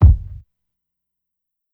KICK_FUNERAL.wav